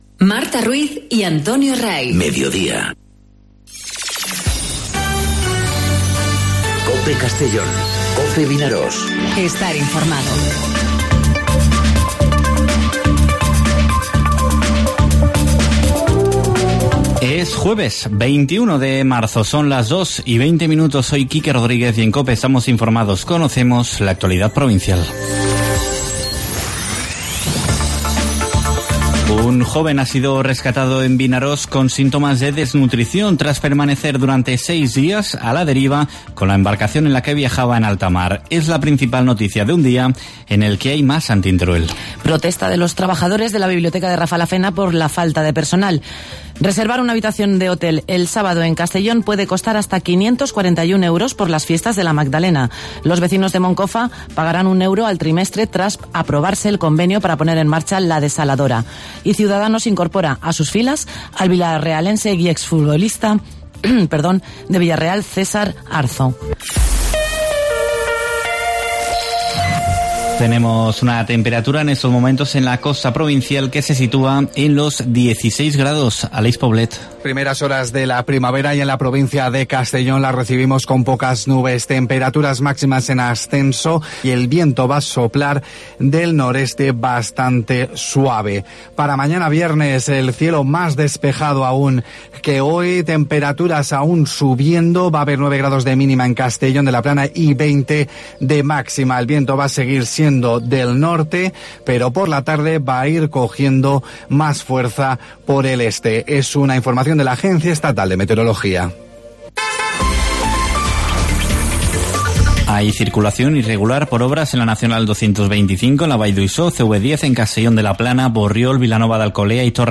Informativo 'Mediodía COPE' en Castellón (21/03/2019)